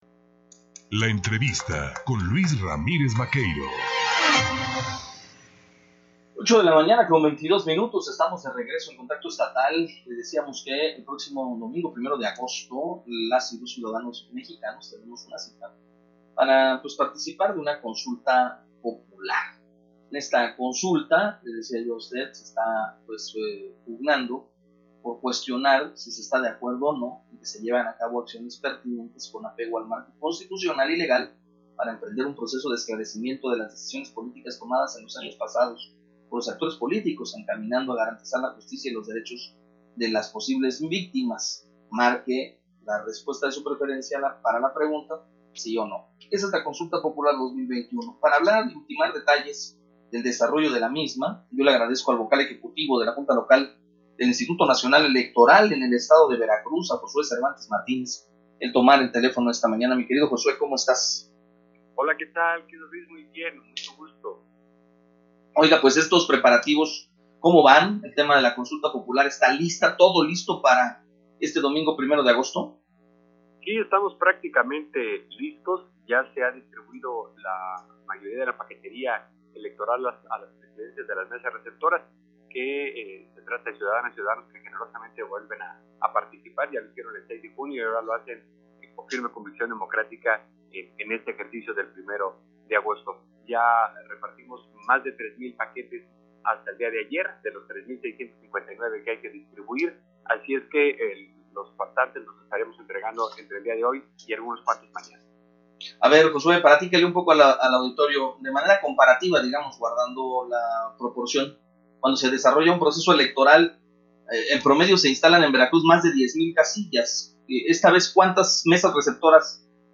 Xalapa. El Vocal Ejecutivo en la Junta Local del Instituto Nacional Electoral (INE) en el estado de Veracruz, Josué Cervantes Martínez, destacó en entrevista para la primera emisión de “En Contacto” que el INE se encuentra listo para la realización de la Consulta Popular a realizarse este 1 de agosto, sin embargo, señaló que por falta de presupuesto solo instalarán tres mil casillas en la entidad,  un tercio en comparación a las que se utilizaron el pasado 6 de junio.